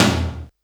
80s Digital Tom 06.wav